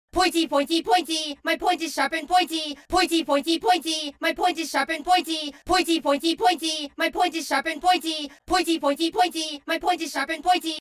OUCH!!!!! sound effects free download